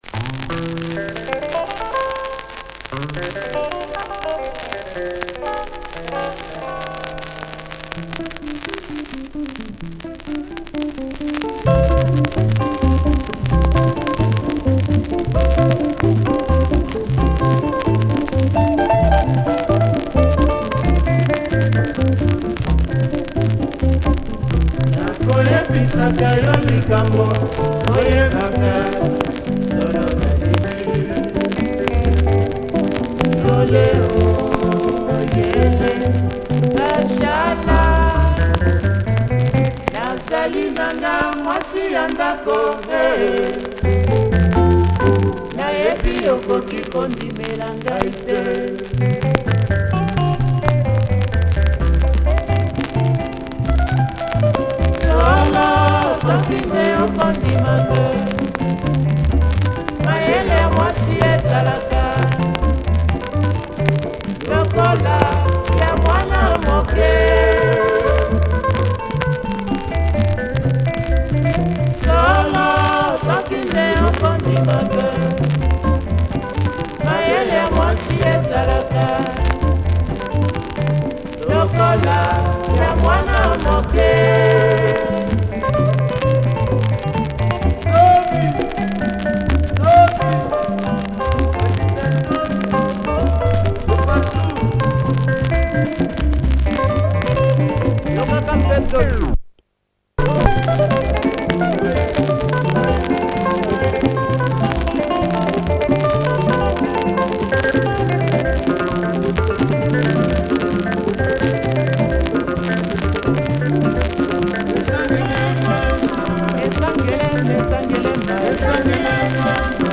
Some dirt still left in the grooves.